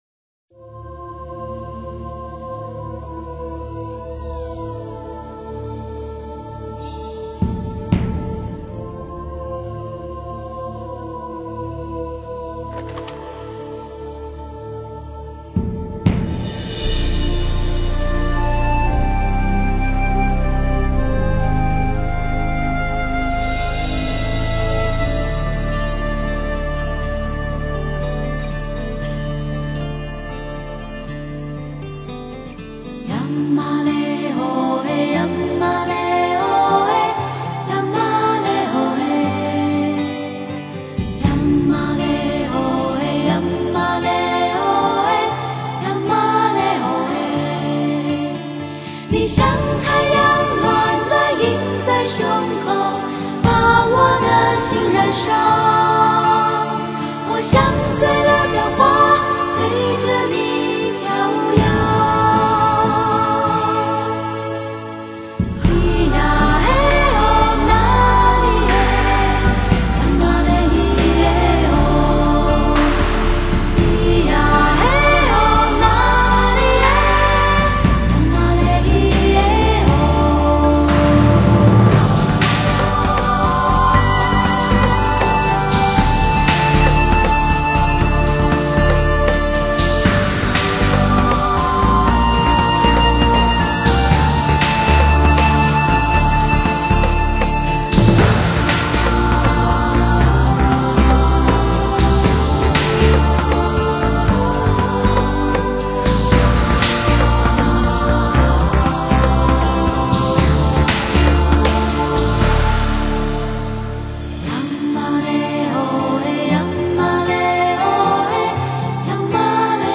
二人的聲音非常相似但又各有特色